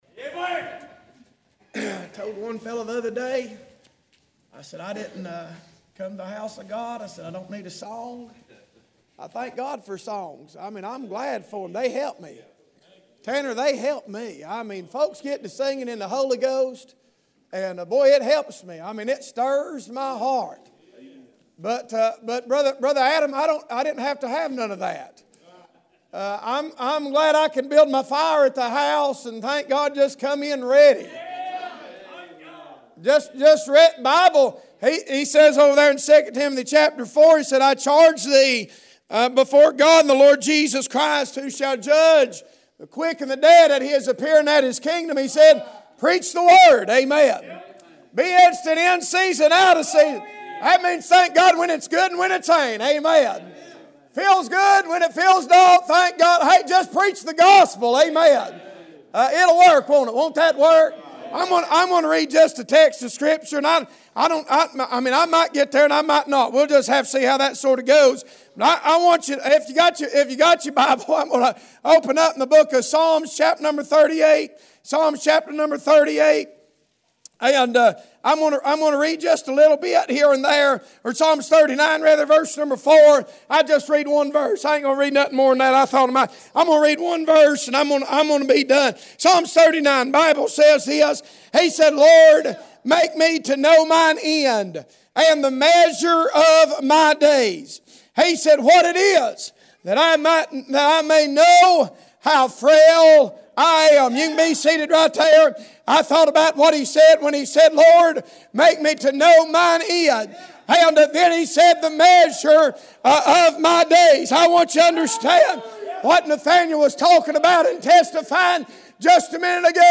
Passage: Psalm 39:4 Service Type: Sunday Morning « March 1